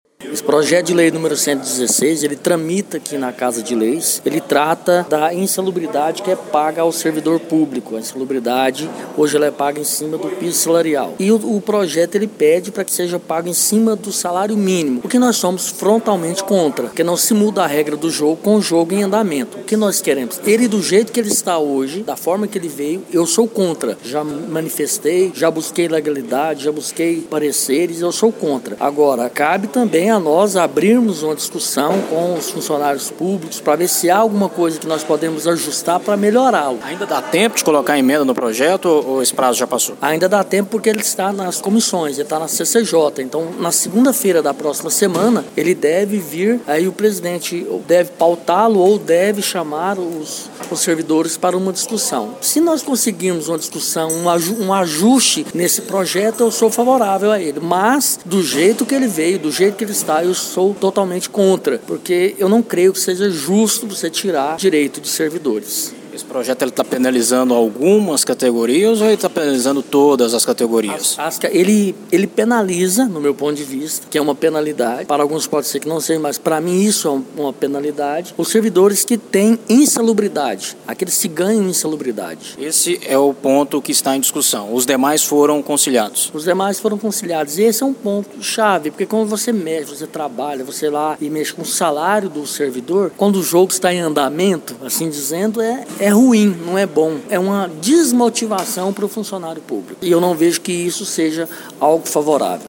Na sessão ordinária da câmara municipal de Morrinhos, o vereador e professor Cayto (PRTB) voltou a falar da sua posição contrária ao projeto de lei 116. O vereador destacou que o projeto ainda necessita de mais debate e que alguns servidores podem ser penalizados.